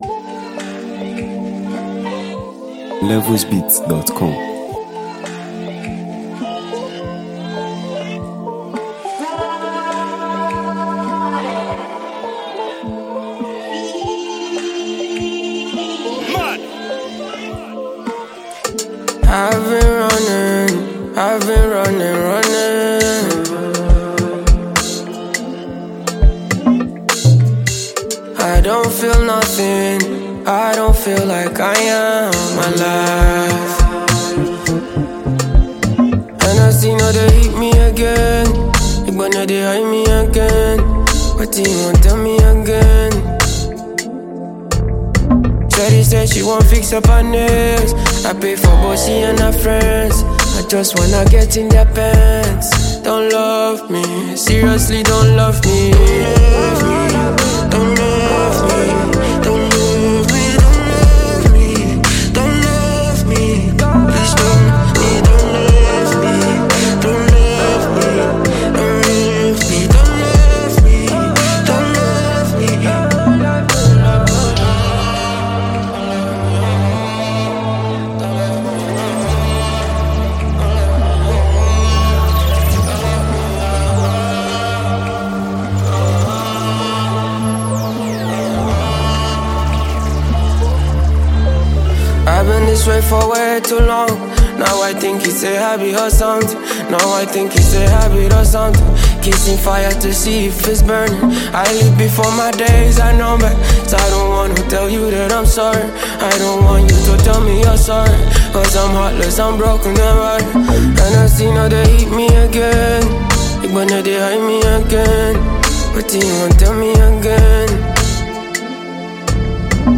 Sensational Nigerian singer-songwriter and Afro-fusion star
smooth melody